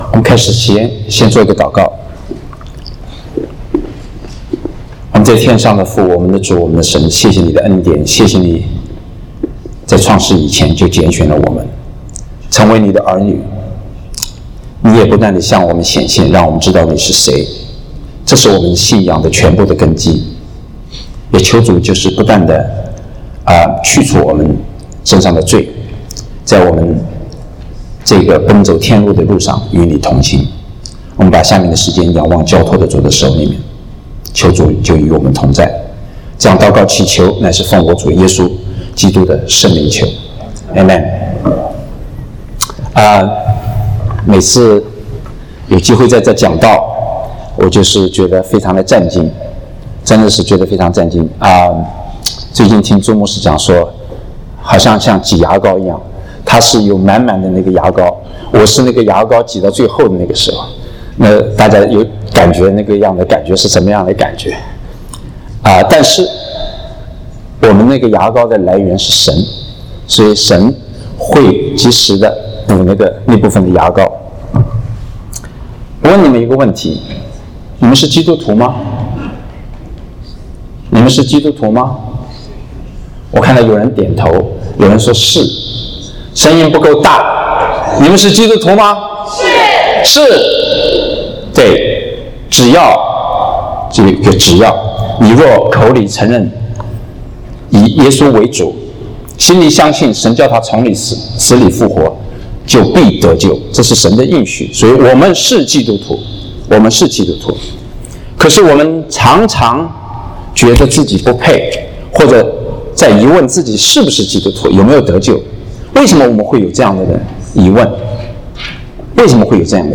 證道